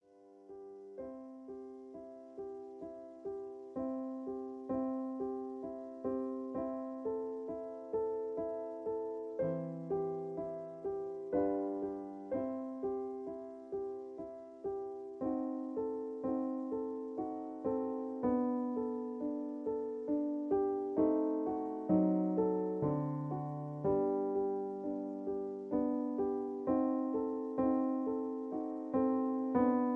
Piano accompaniment in C